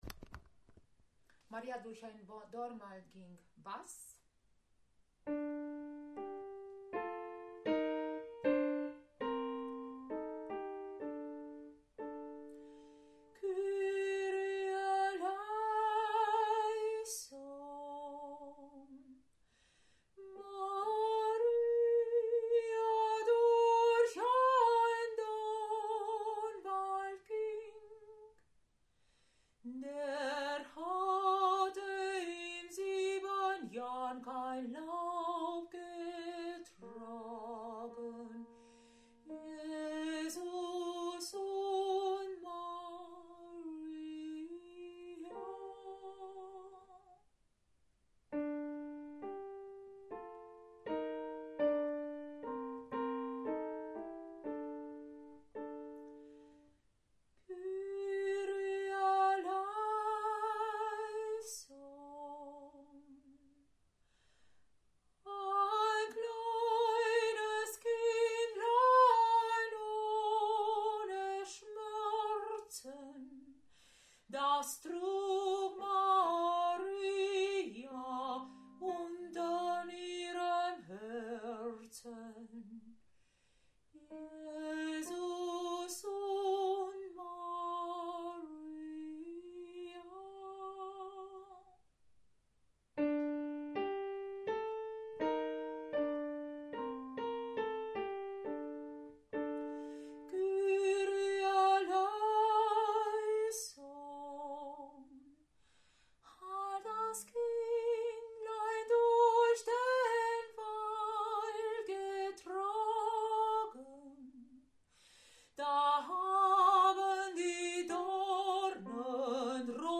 Maria durch den Dornwald ging Bass